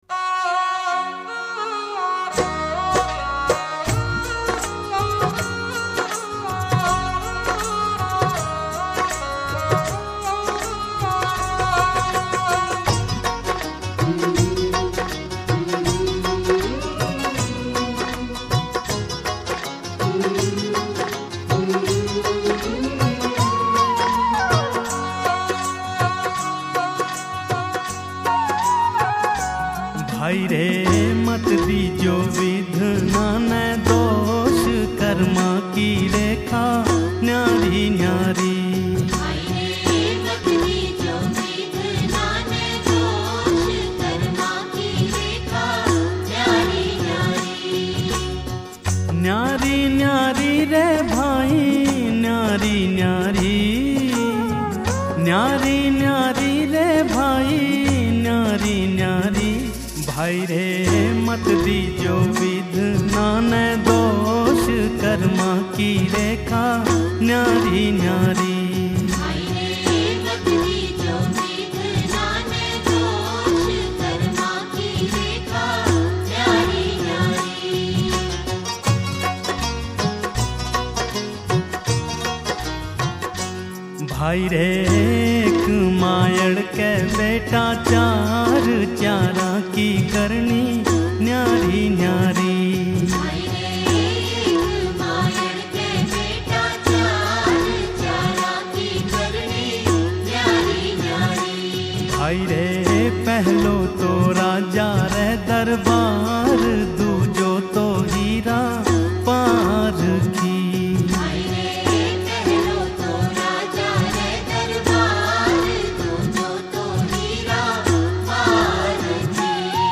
[Devotional]